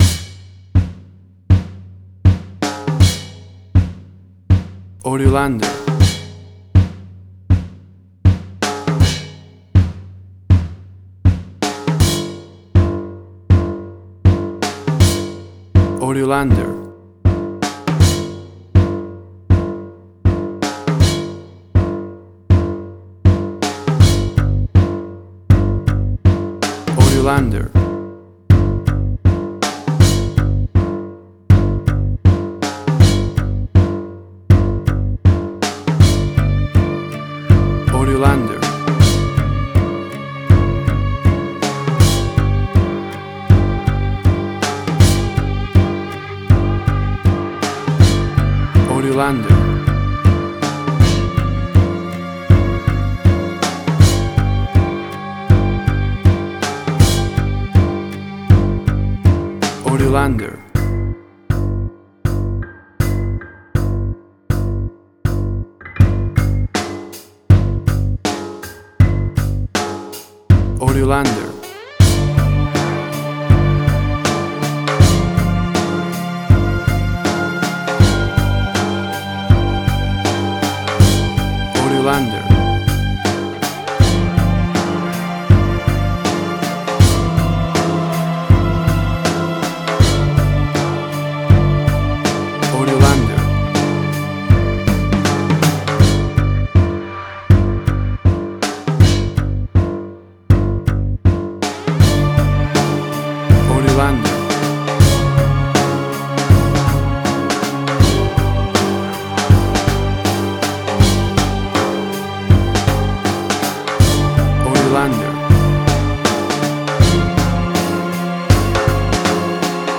Suspense, Drama, Quirky, Emotional.
Tempo (BPM): 80